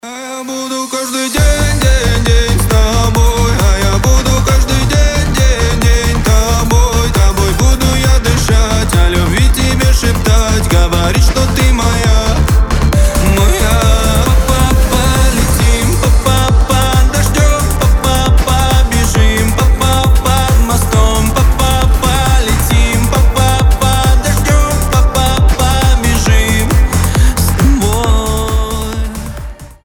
• Качество: 320, Stereo
позитивные
ритмичные
быстрые